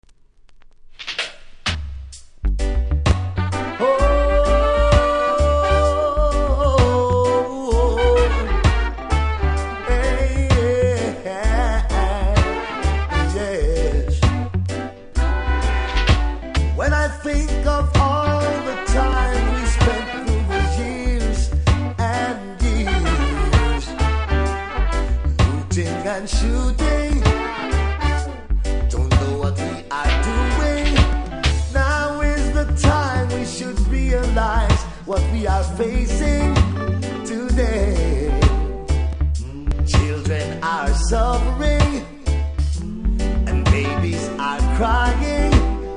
REGGAE 90'S